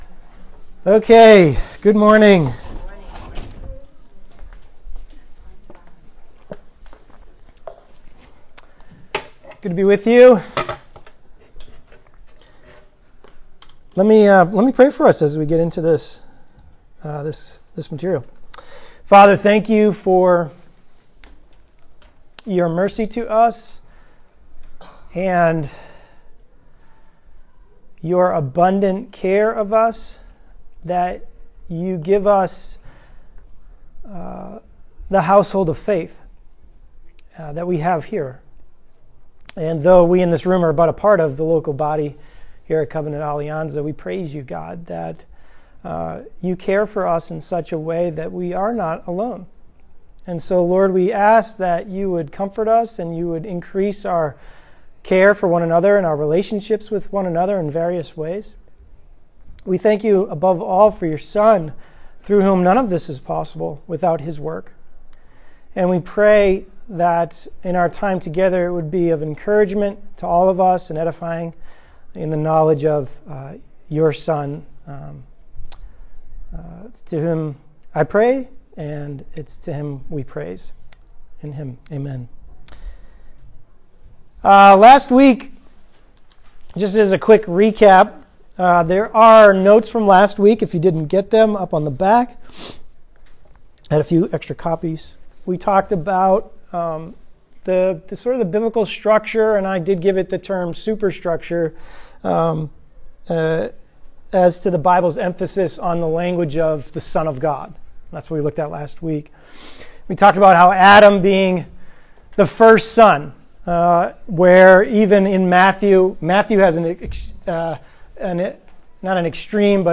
Sunday School Classes